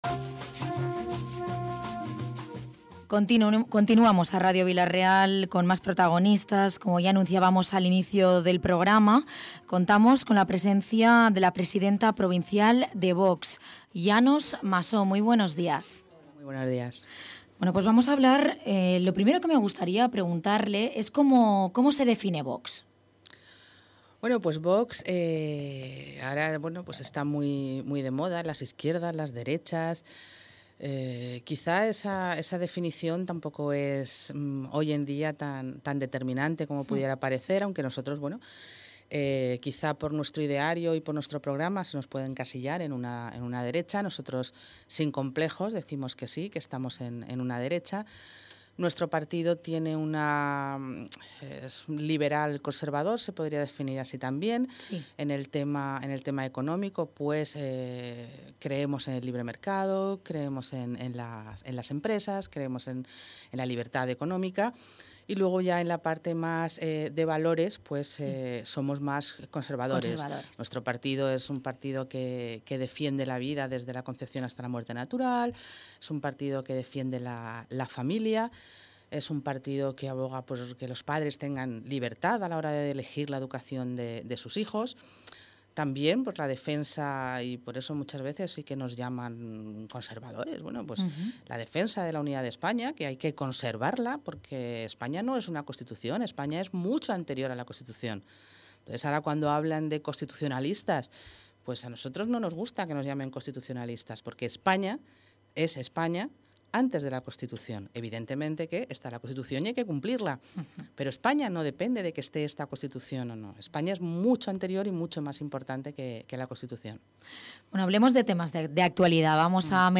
Entrevista: Llanos Massó, presidente de VOX Castellón.
Entrevista completa a María de los Llanos Massó, la presidente de Vox Castellón.